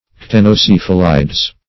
ctenocephalides - definition of ctenocephalides - synonyms, pronunciation, spelling from Free Dictionary
ctenocephalides.mp3